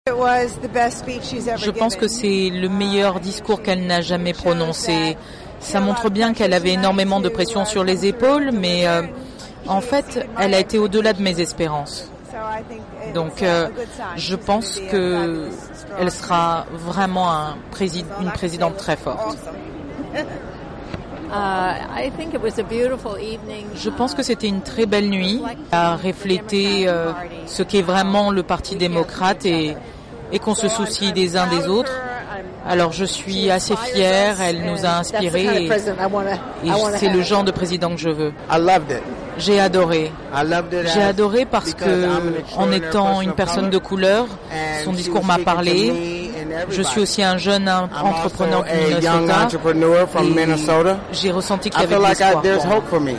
Les réactions de quelques participants au discours de Mme Clinton